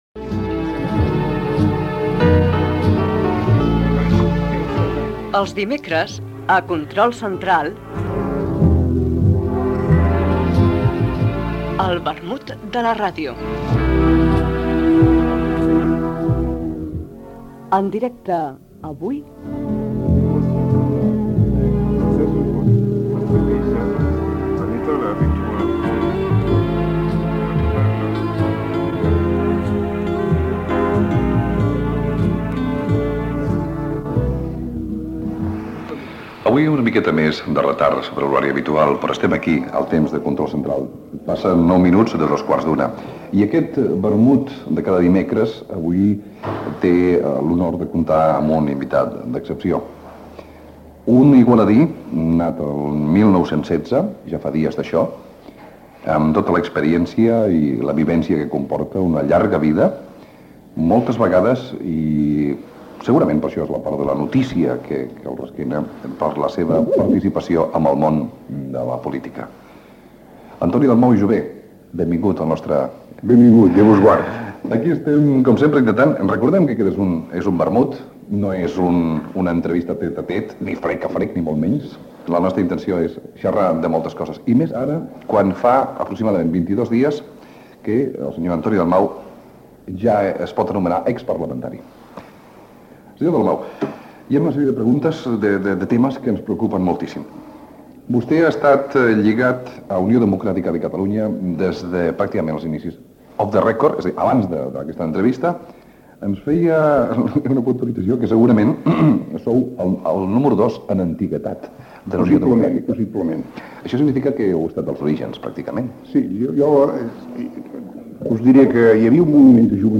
Espai fet en directe des de l'Hostal Canaletas d'Igualda.
entrevista al polític Antoni Dalmau Jover d'Unió Democràtica de Catalunya